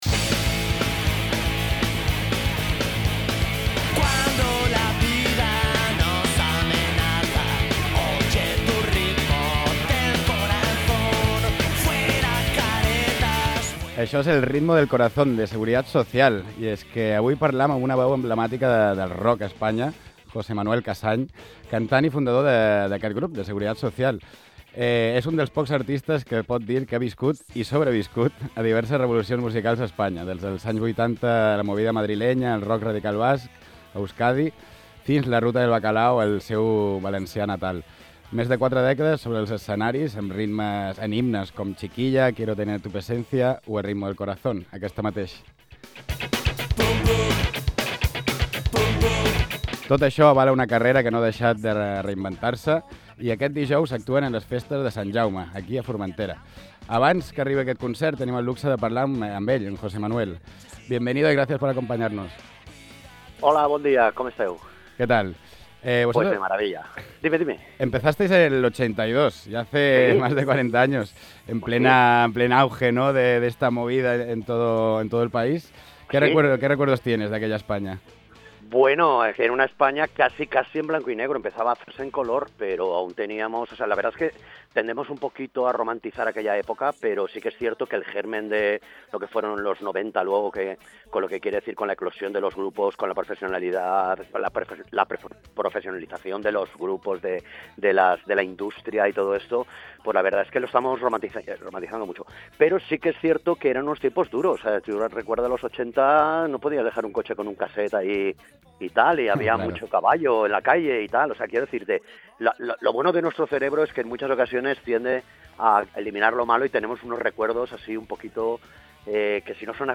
Avui hem parlat amb una veu emblemàtica del rock en castellà, José Manuel Casañ, cantant i fundador del grup Seguridad Social.